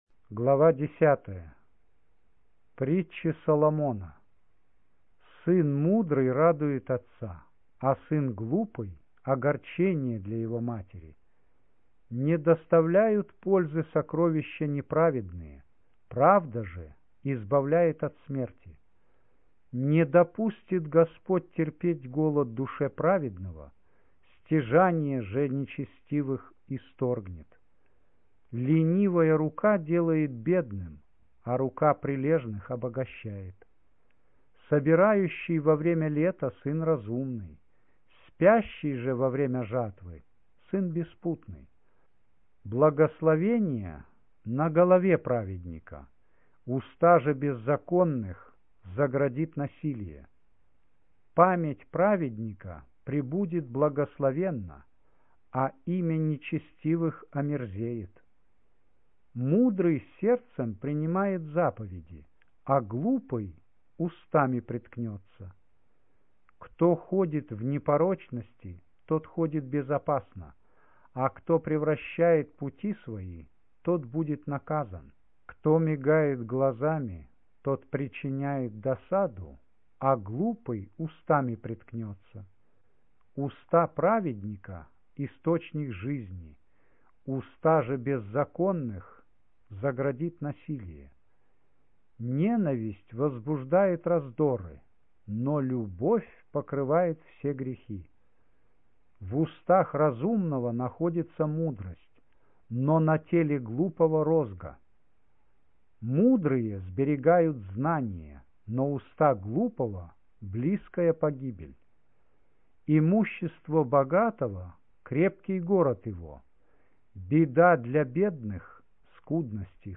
Аудио Библия